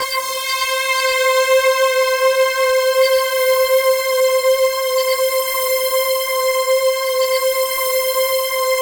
Index of /90_sSampleCDs/Infinite Sound - Ambient Atmospheres/Partition C/07-RANDOMPAD